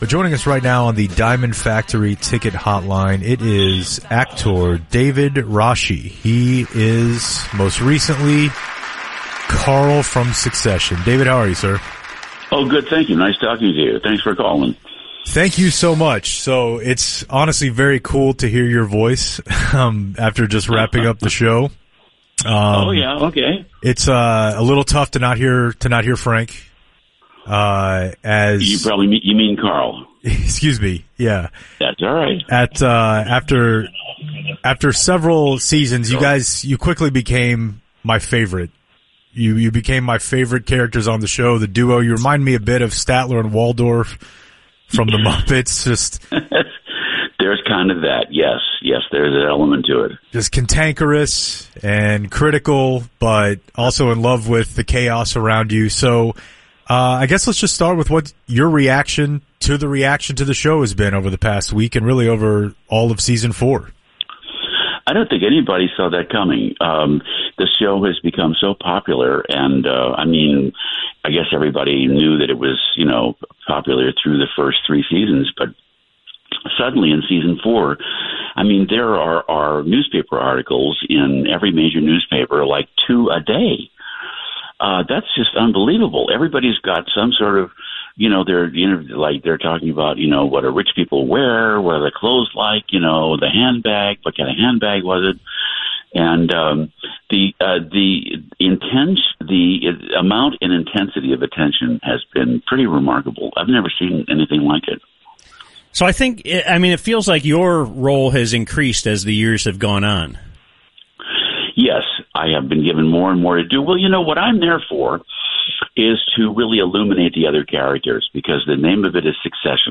Interview: David Rasche from Succession - The Hang Zone 6.1.2023 - The UnTicket